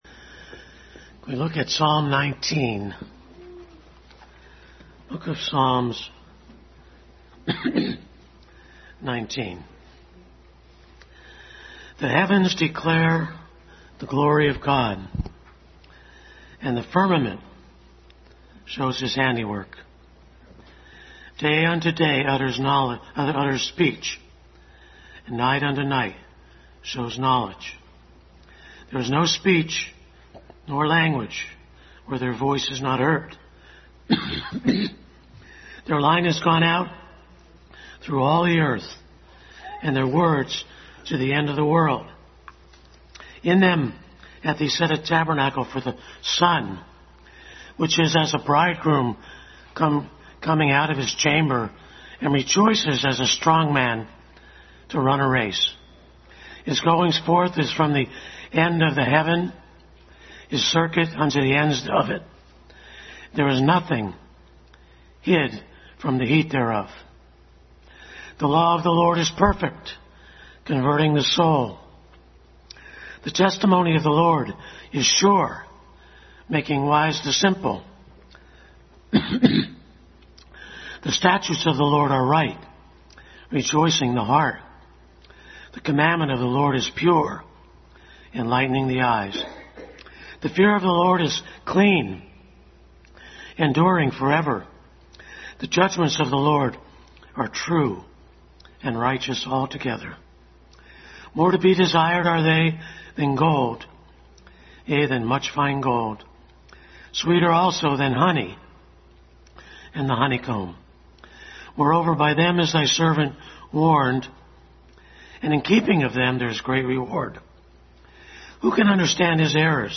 Psalm 19 Passage: Psalm 19:1-14, Romans 1:18-24 Service Type: Family Bible Hour Family Bible Hour message.